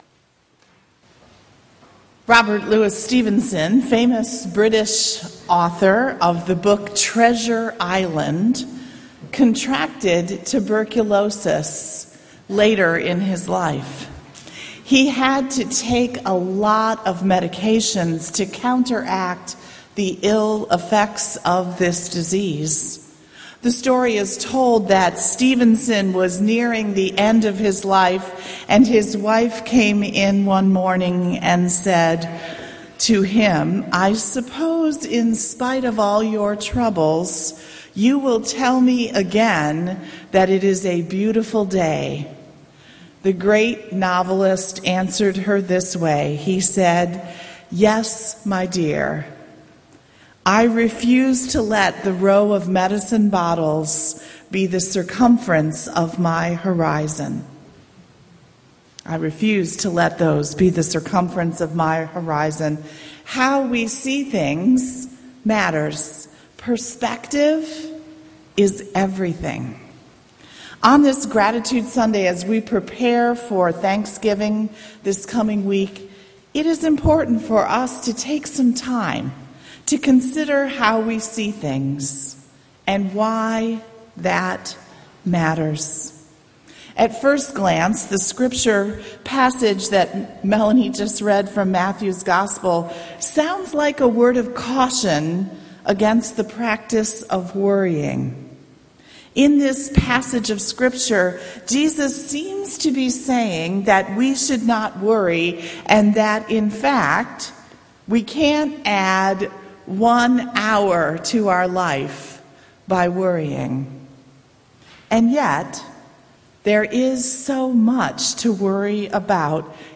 November-18th-Sermon.mp3